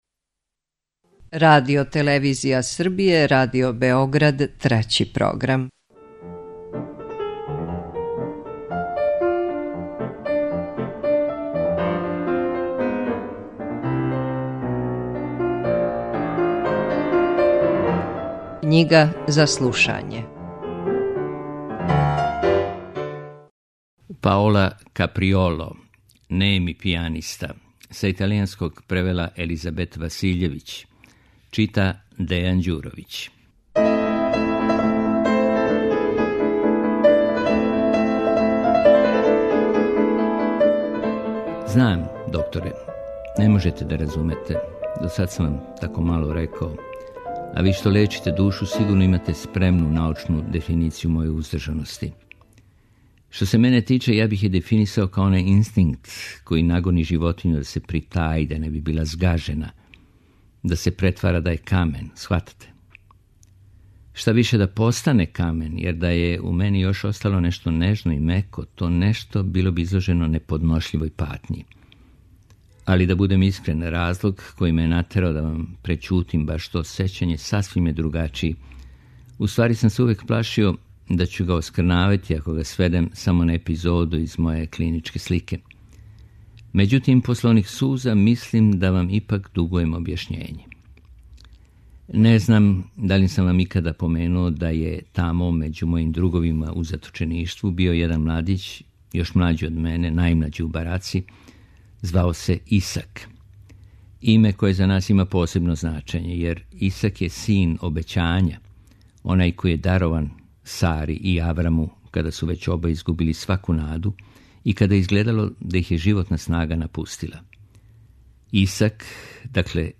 Књига за слушање